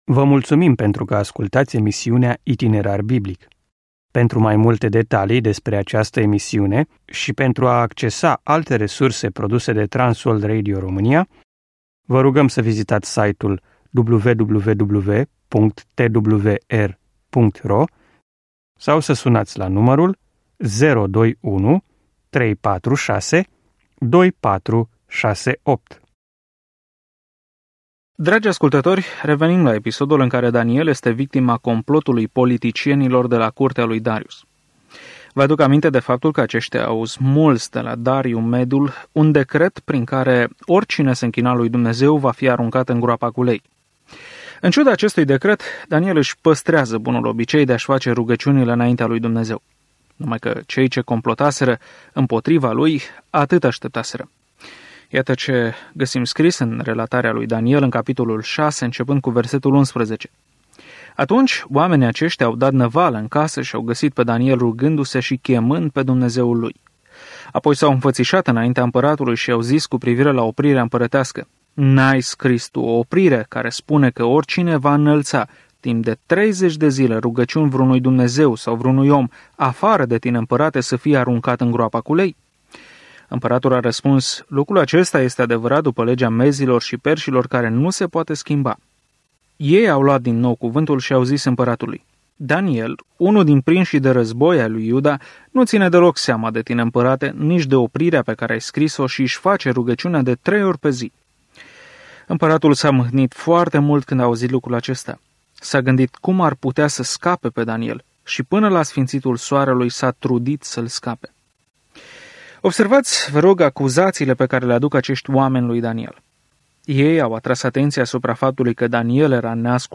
Scriptura Daniel 6:12-28 Daniel 7:1-4 Ziua 10 Începe acest plan Ziua 12 Despre acest plan Cartea lui Daniel este atât o biografie a unui om care a crezut pe Dumnezeu, cât și o viziune profetică despre cine va conduce lumea în cele din urmă. Călătoriți zilnic prin Daniel în timp ce ascultați studiul audio și citiți versete selectate din Cuvântul lui Dumnezeu.